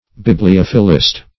Bibliophilist \Bib`li*oph"i*list\, n. A lover of books.
bibliophilist.mp3